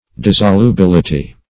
Dissolubility \Dis`so*lu*bil"i*ty\, n.